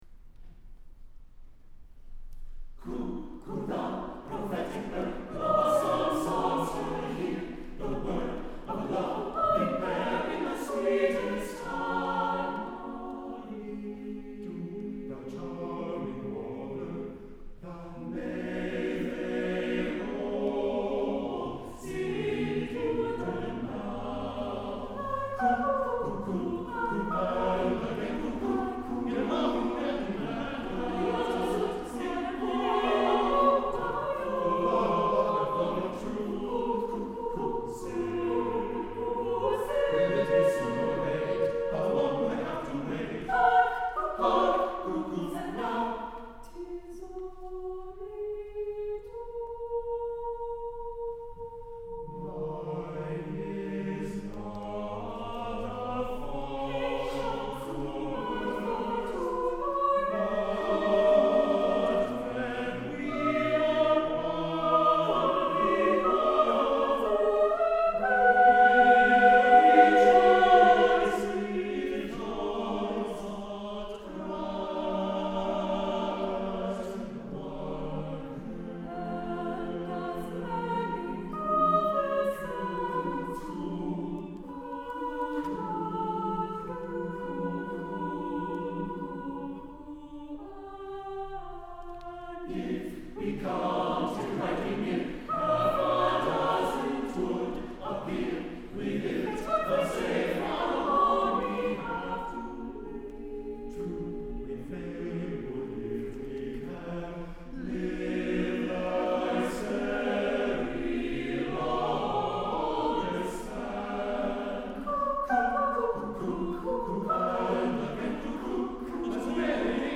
for SATB chorus a cappella